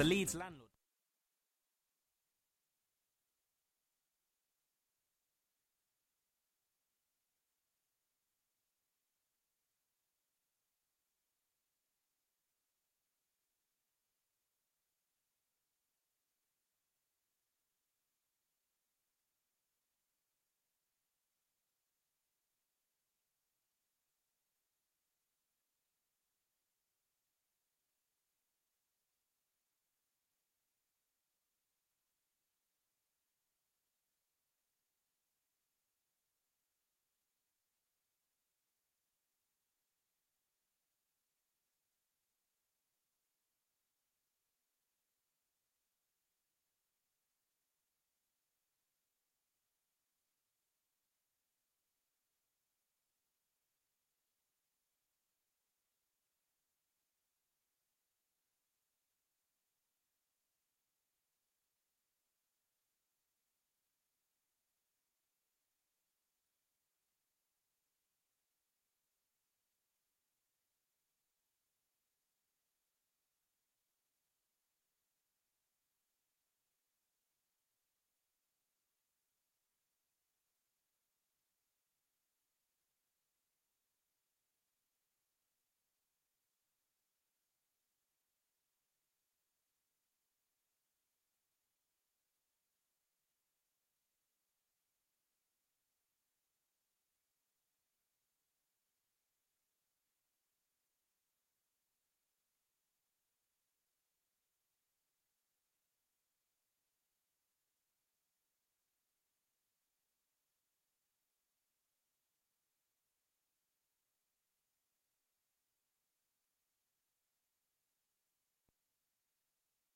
with 2 minutes silence at the beginning of the show for Remembrance Tuesday (11/11/14)